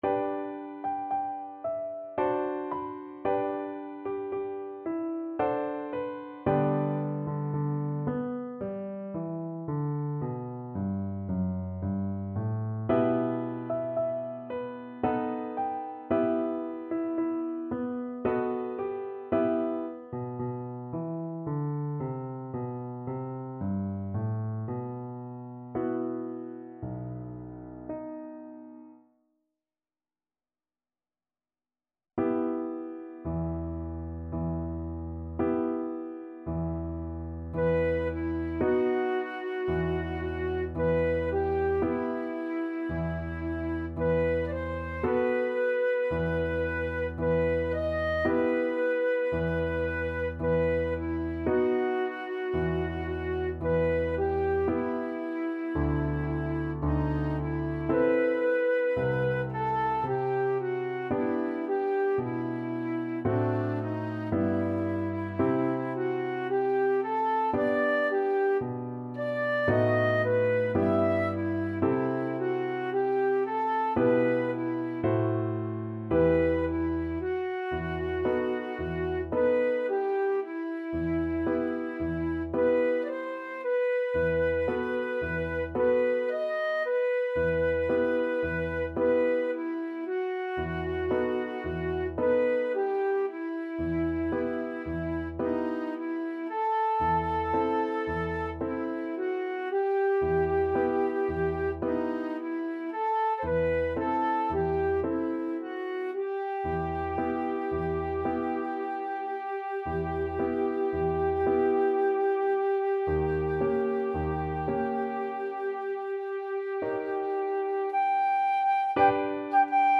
Flute
~ = 56 Andante
G major (Sounding Pitch) (View more G major Music for Flute )
3/4 (View more 3/4 Music)
Classical (View more Classical Flute Music)
tchaik_serenade_melancolique_FL.mp3